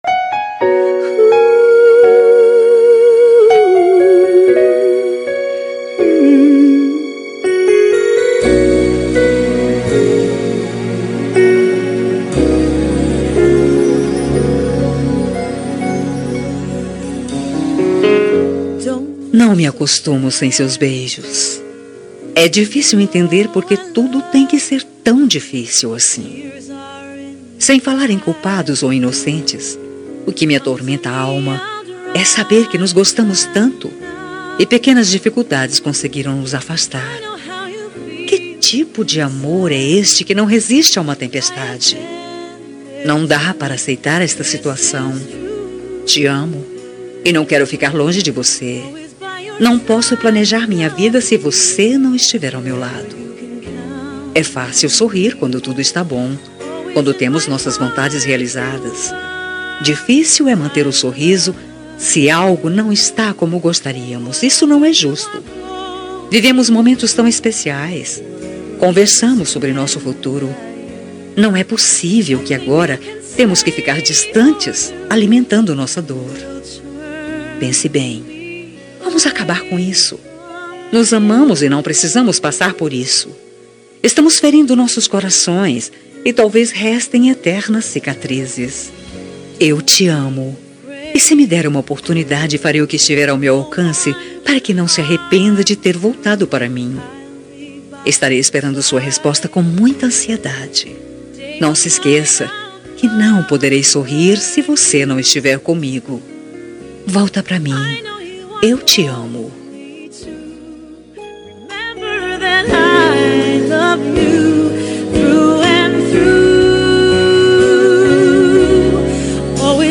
Telemensagem de Reconciliação Romântica – Voz Feminina – Cód: 971